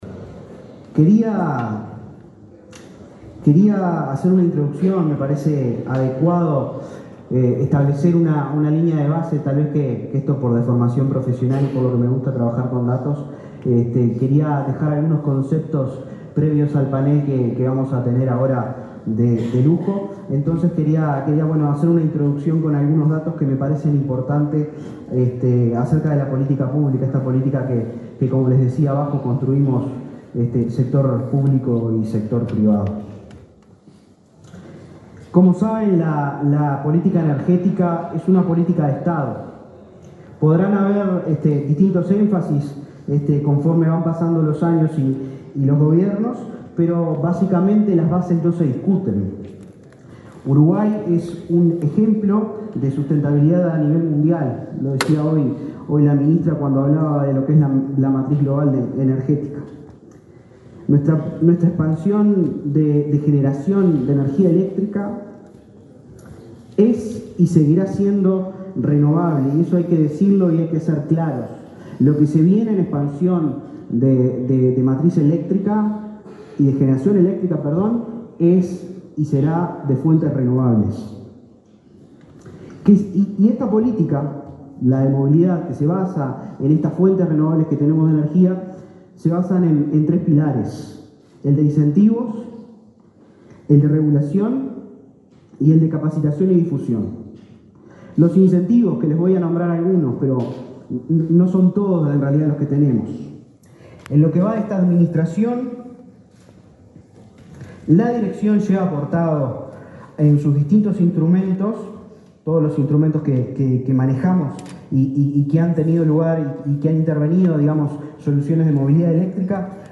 Palabras del director nacional de Energía, Christian Nieves
Palabras del director nacional de Energía, Christian Nieves 27/09/2024 Compartir Facebook X Copiar enlace WhatsApp LinkedIn Este viernes 27 en Montevideo, se realizó la Expo Movilidad Eléctrica y el 4.° Foro Internacional en la materia. El director nacional de Energía, Christian Nieves, expuso en el primer panel del evento.